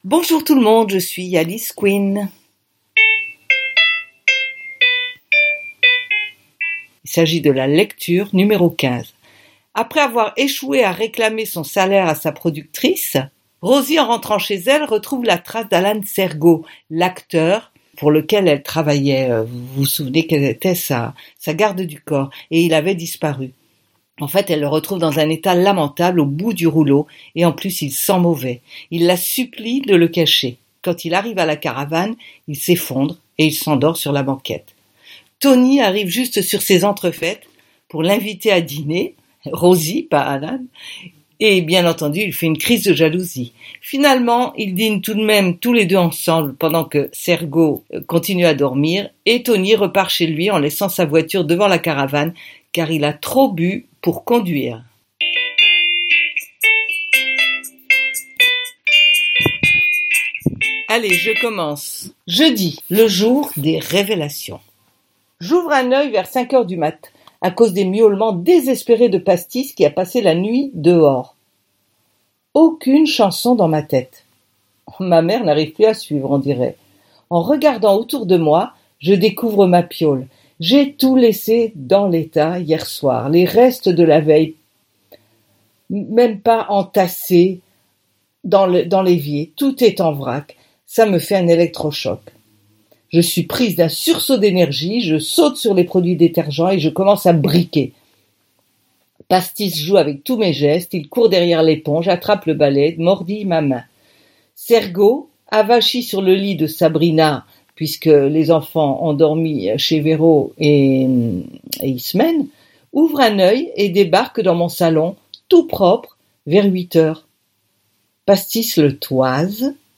Lecture #15 Ce roman fait partie de la série AU PAYS DE ROSIE MALDONNE.